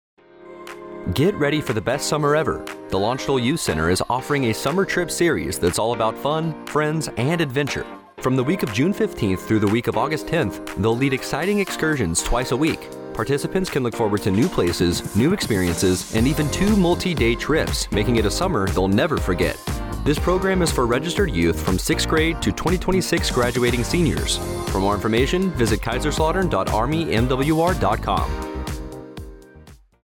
A 30-second radio spot on the Landstuhl Youth Center summer trips that will air on AFN Kaiserslautern from April 17, 2026, to Aug. 10, 2026.